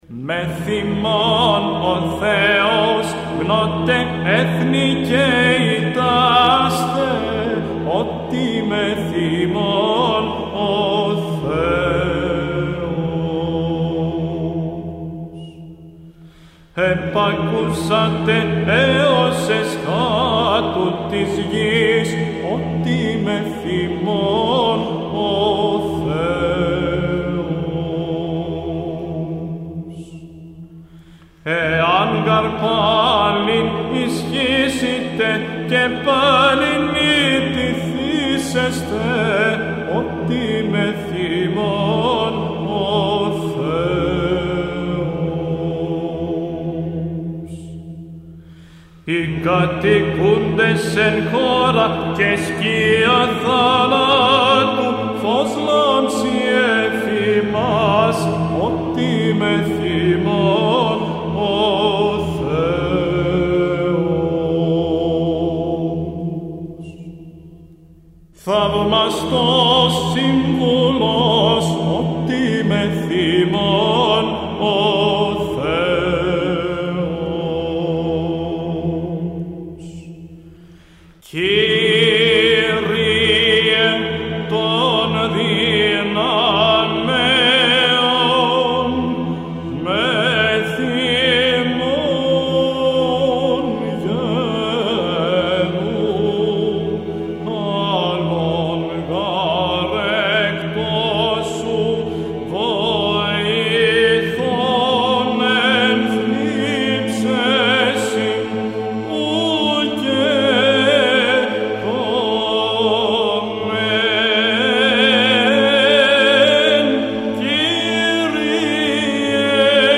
Late Byzantine Chant Style:Mp3 Sound File